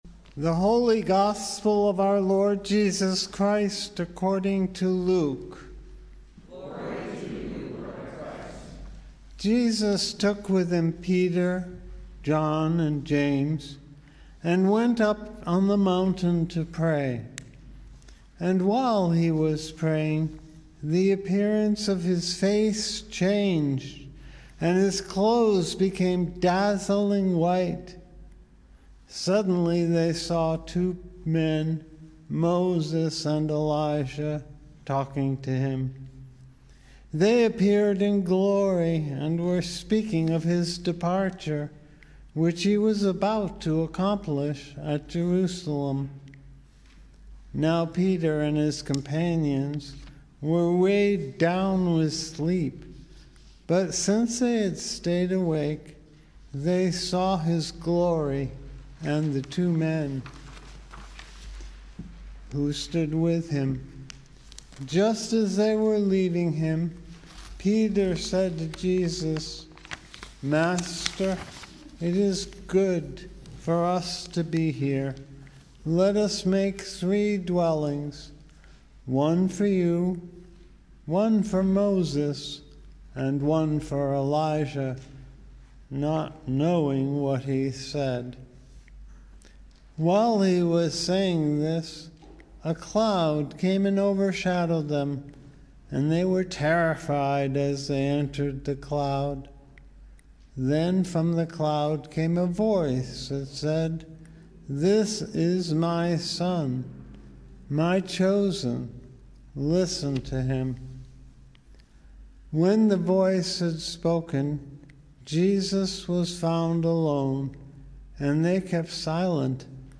Gospel & Sermon, February, 27, 2022 - St. Andrew's Episcopal Church